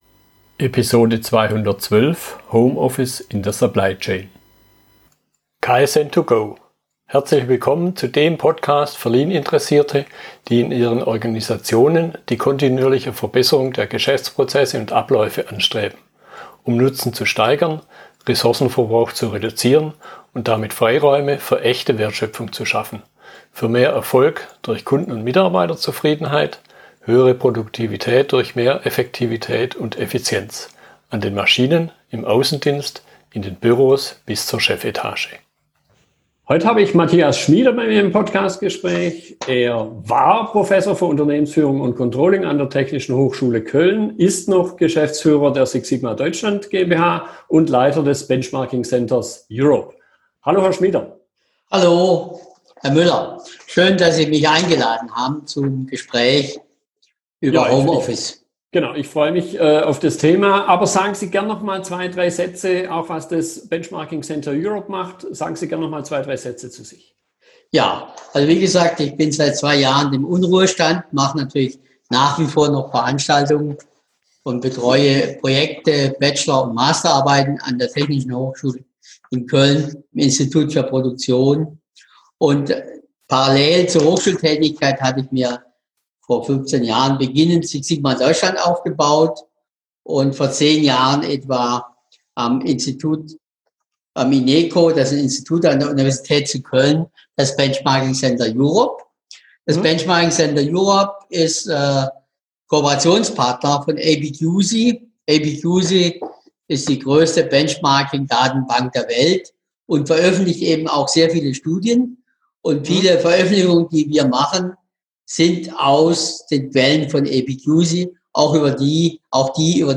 Fragestellungen aus dem Gespräch